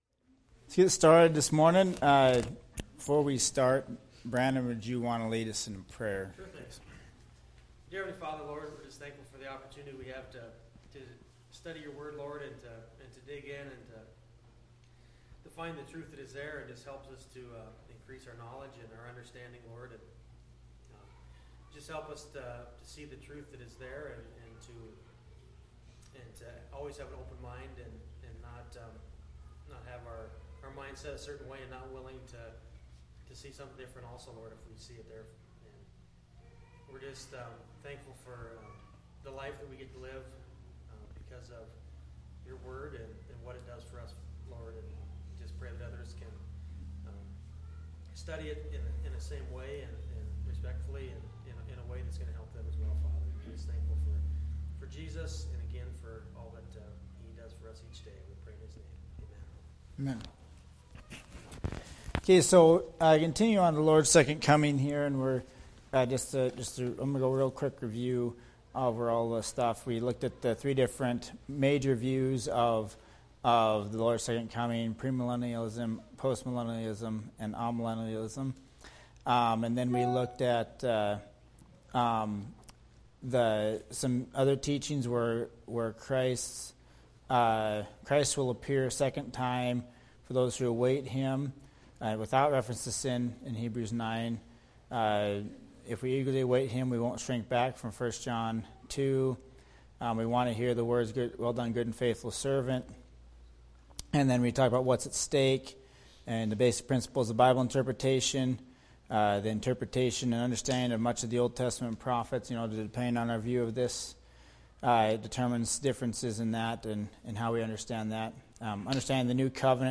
Bible Class « John 6:41-44